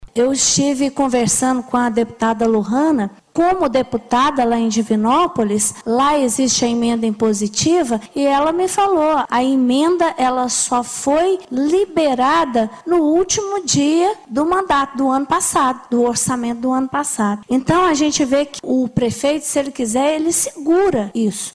Clique e Ouça Vereadora Irene Melo Franco